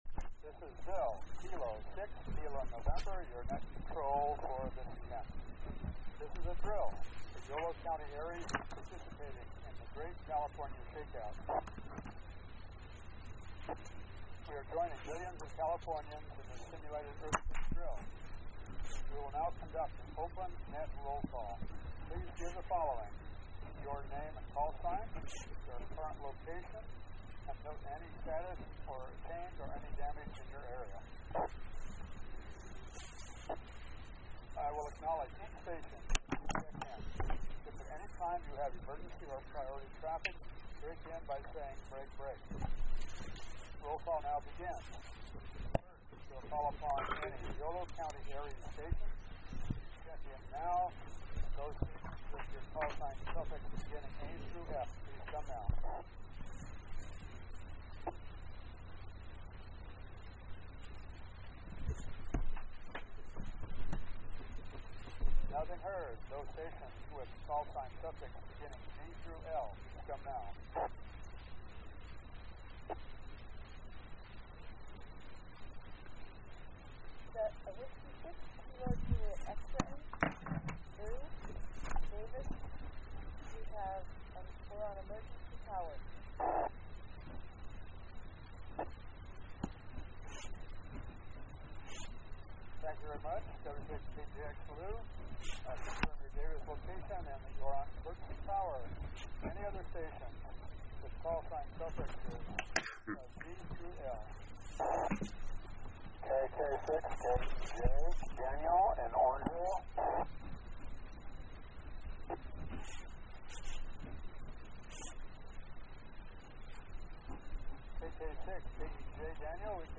Yolo County ARES joined rnearly 10 millions Californians in the Great California Shakeout earthquake drill on October 16, 2014 starting at 10:16 AM.
Exercise plan Recording of the 10:30 AM net Recording of the 12:30 PM net Recording of the 5:30 PM net Recording of the 7:30 PM net Great California Shakeout logs of check-ins and location Yolo County ARES